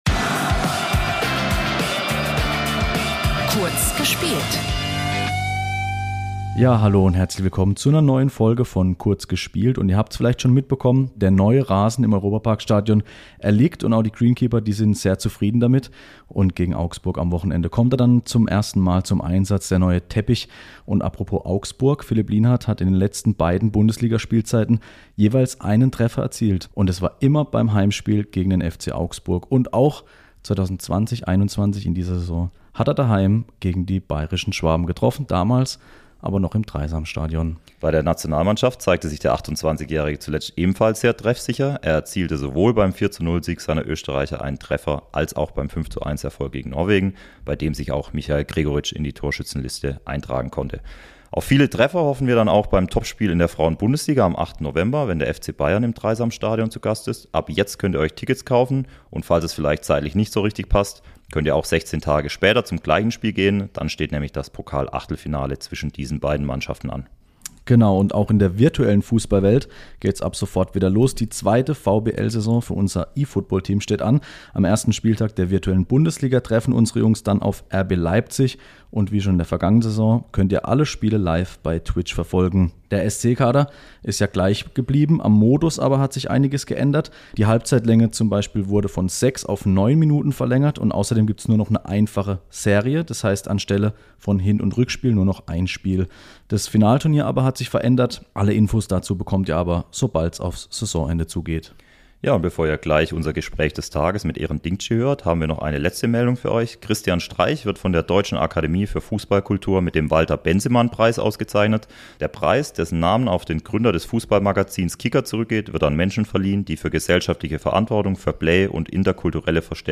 In Folge zwei unseren neuen Kurzformats ist Eren Dinkci zu Gast. Der 22-Jährige spricht im Interview unter anderem über seine Nominierung für die türkische Nationalmannschaft.
Außerdem hört ihr Trainer Julian Schuster und bekommt wieder einen Überblick über die aktuelle Ausgabe unseres Heimspiel-Magazins.